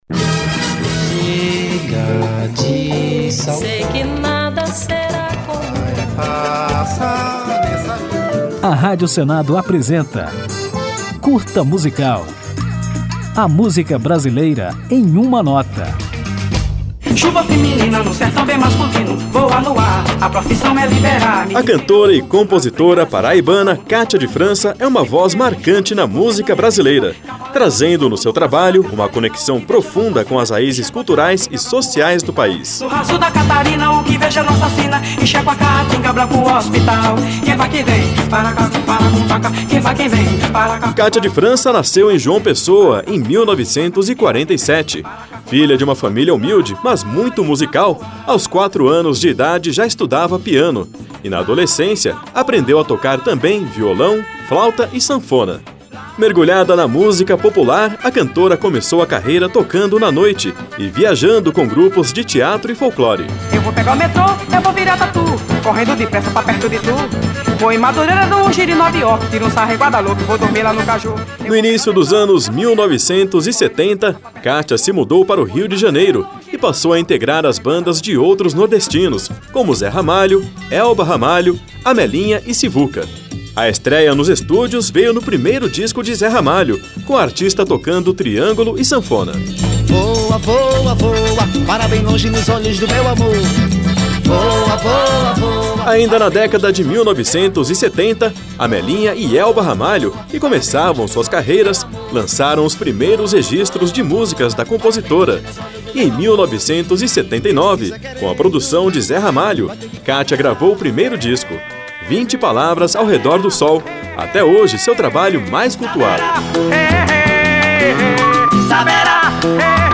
Depois de apresentada a história de Cátia de França, vamos ouvi-la na música 20 Palavras Girando ao Redor do Sol, presente em seu primeiro disco, lançado em 1979.